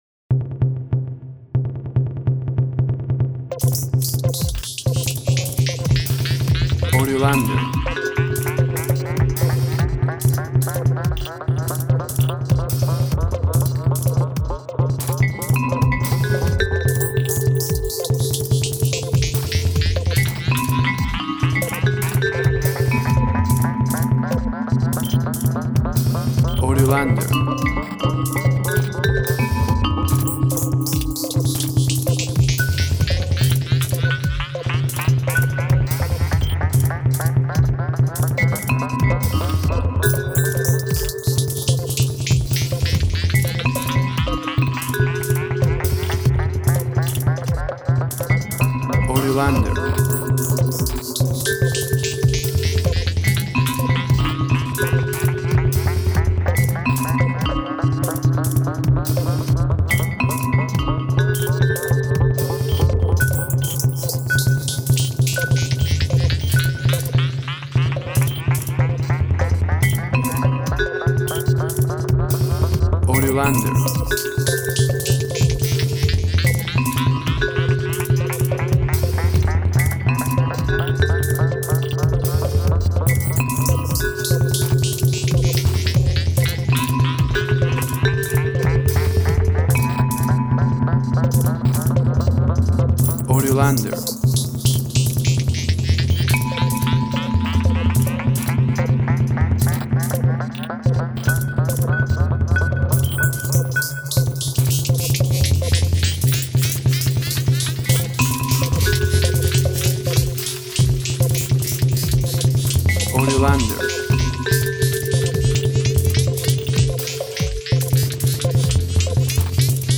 Electric percussion, mystery.
Tempo (BPM) 120